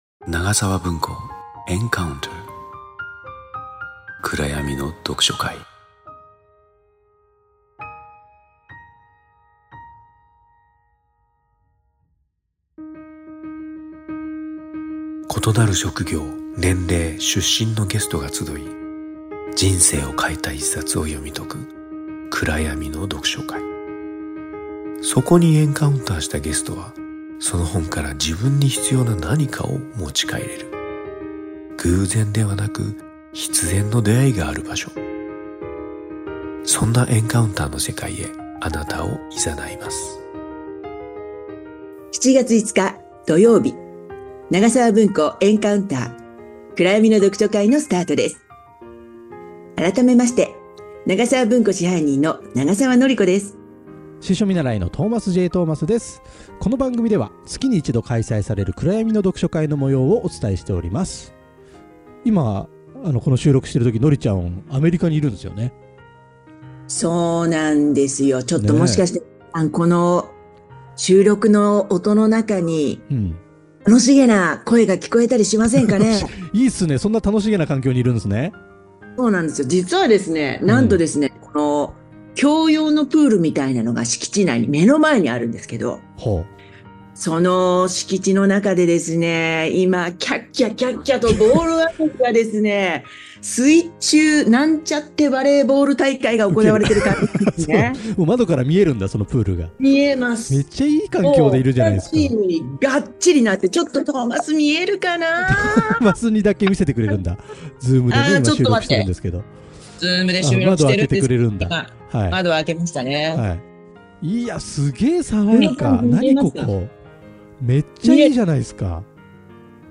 【PODCAST】長澤文庫Encounter-暗闇の読書会- – 東京のとある場所で毎月一度開催される暗闇の読書会。その模様をPODCASTでお届けします。